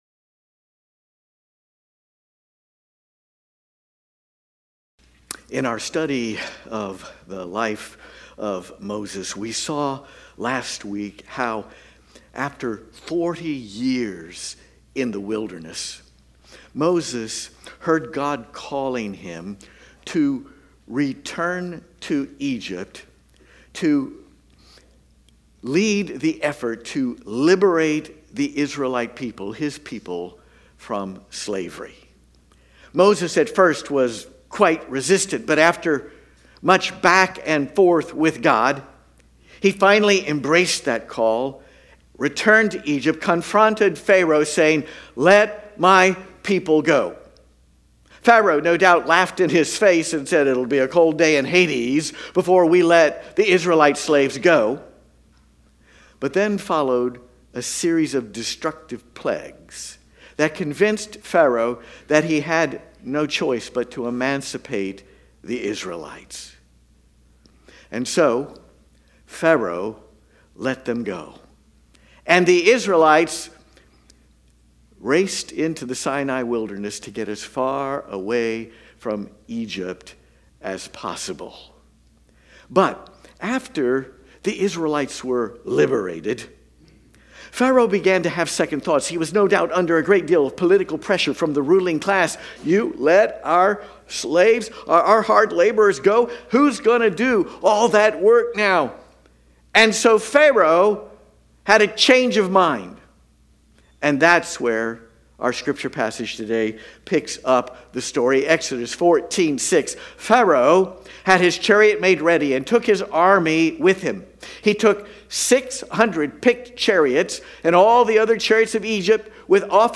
Watch the entire Worship Service